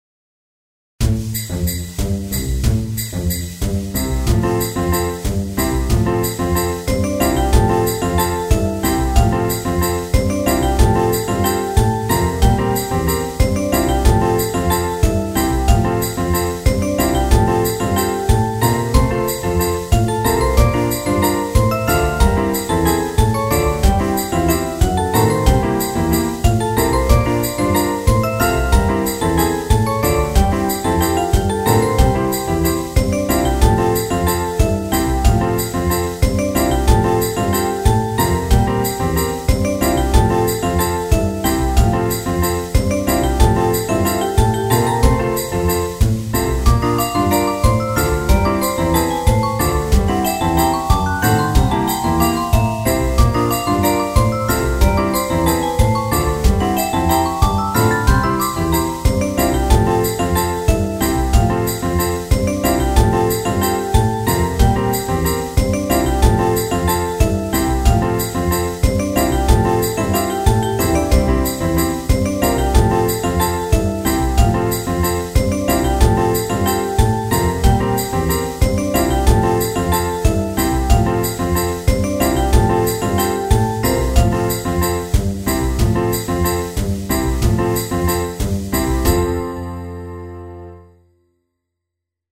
カントリーショート明るい穏やか